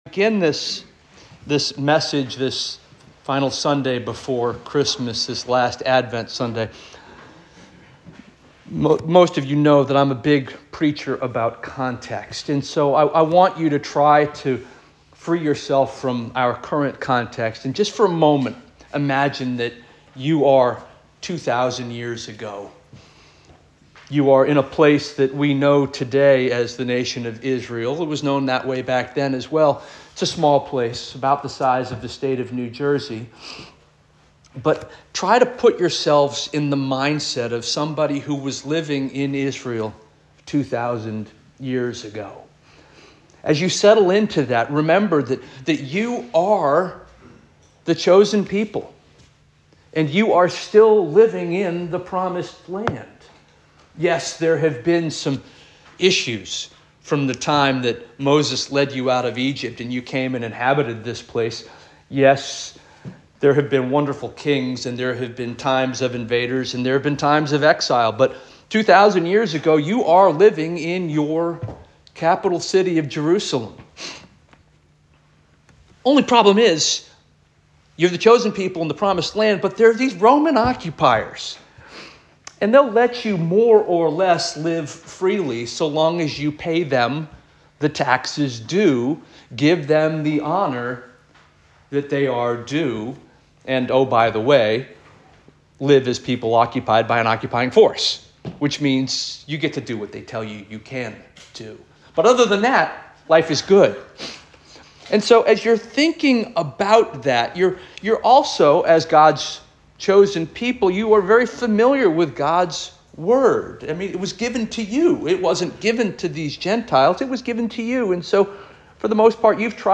December 22 2024 Sermon